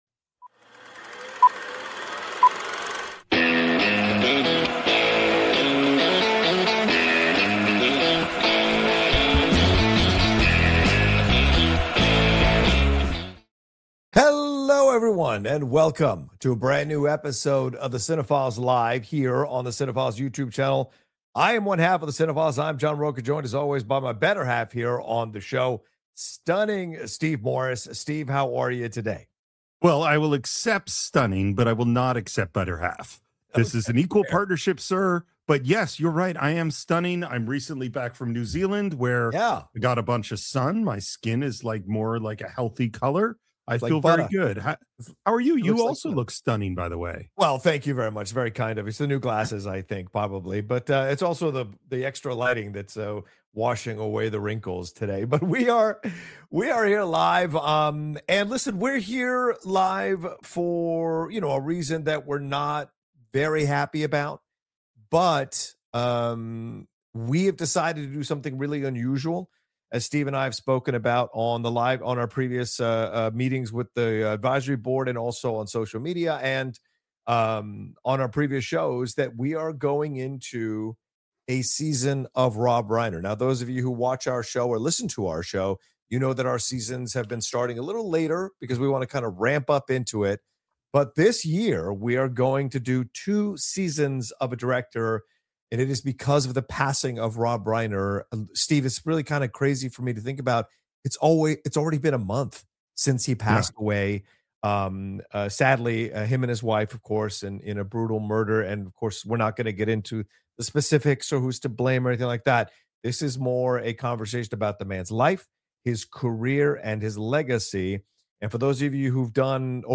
in depth discussion